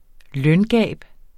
Udtale [ ˈlœn- ]